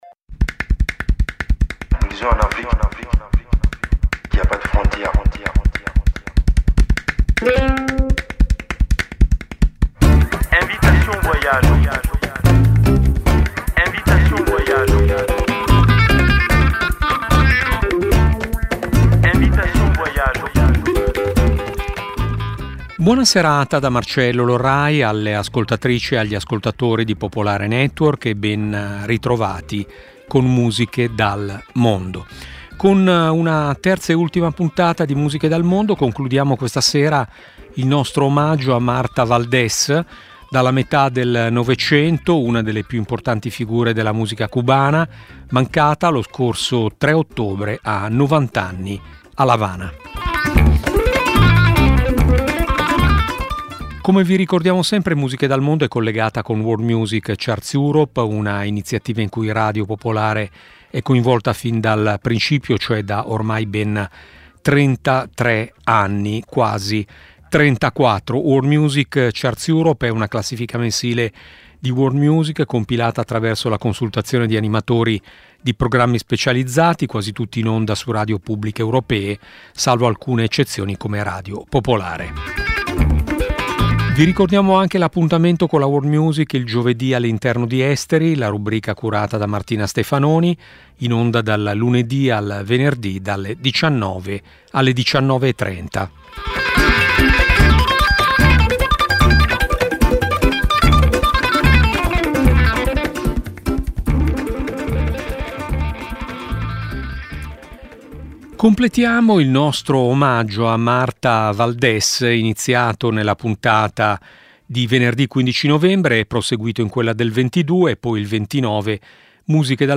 Musiche dal mondo è una trasmissione di Radio Popolare dedicata alla world music, nata ben prima che l'espressione diventasse internazionale.
Un'ampia varietà musicale, dalle fanfare macedoni al canto siberiano, promuovendo la biodiversità musicale.